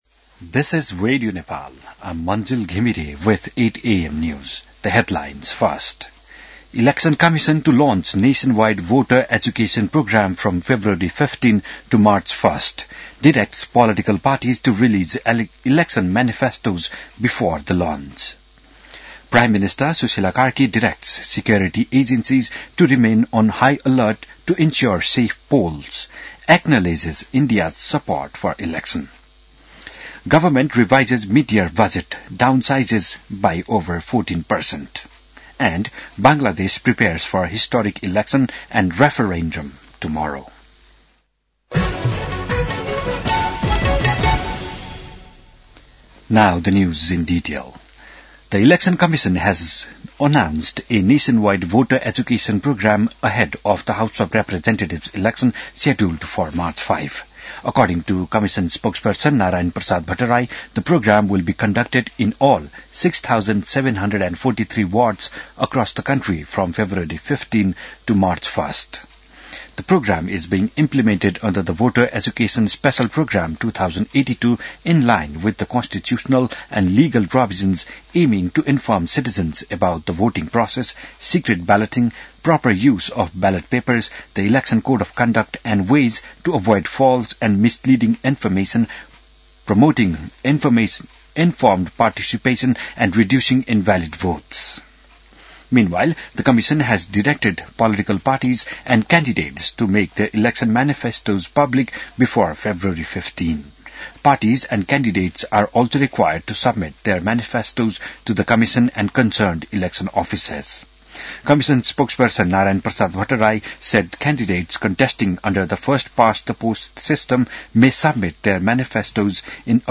An online outlet of Nepal's national radio broadcaster
बिहान ८ बजेको अङ्ग्रेजी समाचार : २८ माघ , २०८२